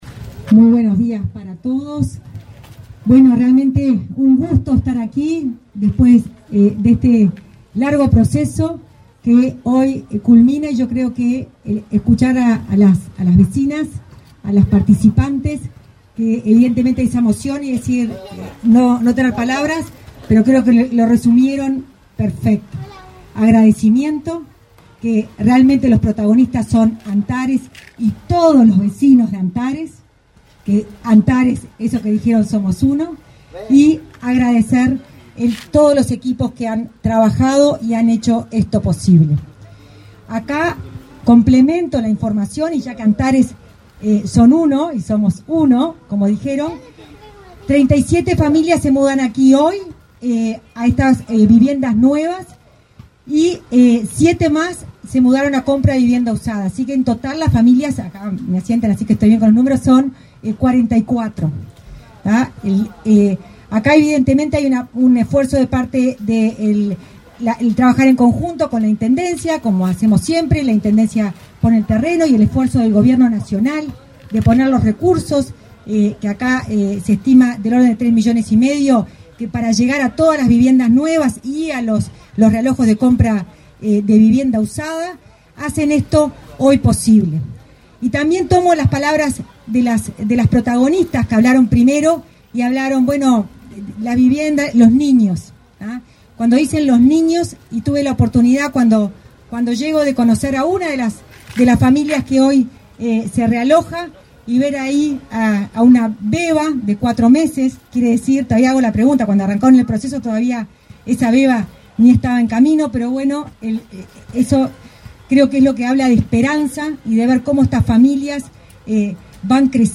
Acto de entrega de viviendas del plan Avanzar en Montevideo
Acto de entrega de viviendas del plan Avanzar en Montevideo 13/02/2025 Compartir Facebook X Copiar enlace WhatsApp LinkedIn El Ministerio de Vivienda y Ordenamiento Territorial entregó, este 13 de febrero, 37 viviendas para el realojo de familias que vivían en el asentamiento Antares, en Montevideo. Participaron en el evento el titular de la citada cartera, Raúl Lozano, y la directora nacional de Integración Social y Urbana, Florencia Arbeleche.